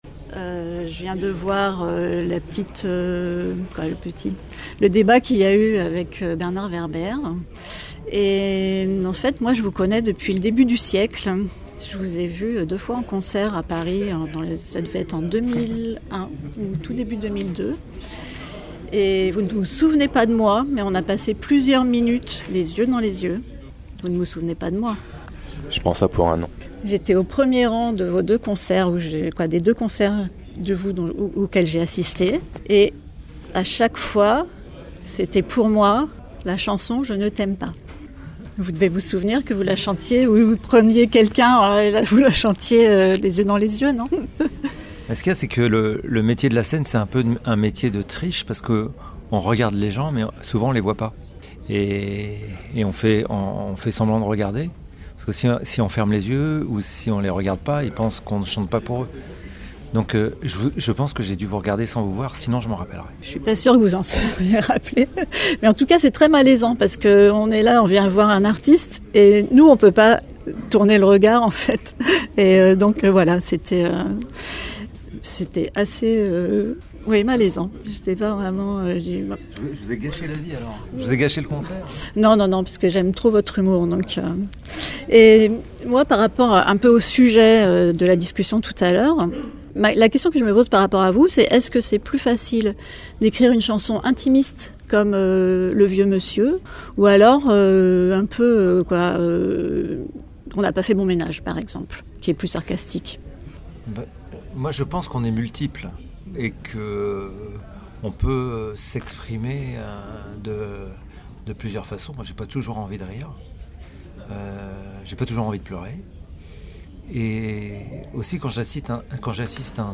Interview de Vincent Baguian (9:35)